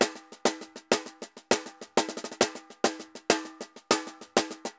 Tambor_Samba 100_1.wav